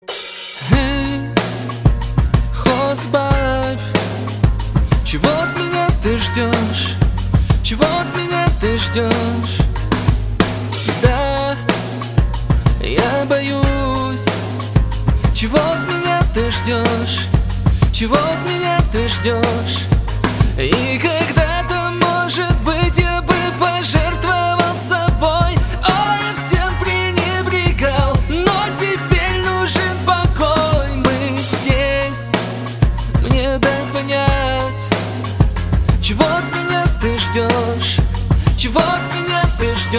pop rock
рок